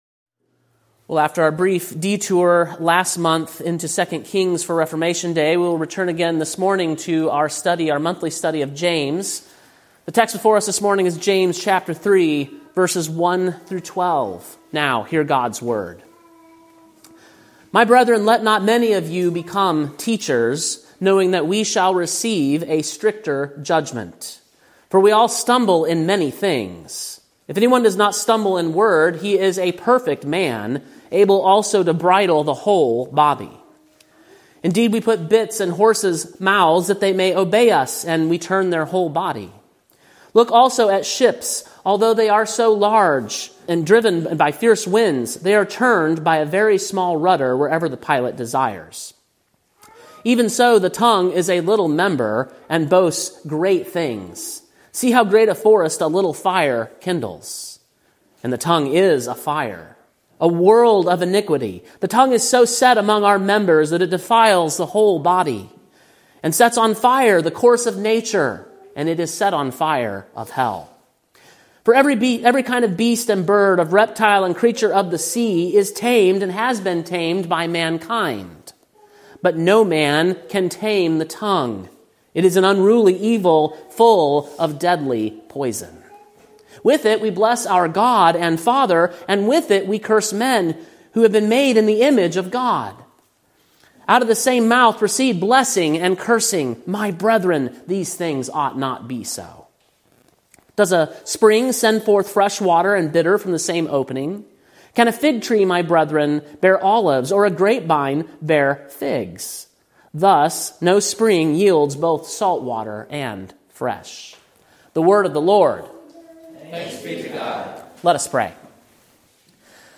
Sermon preached on November 17, 2024, at King’s Cross Reformed, Columbia, TN.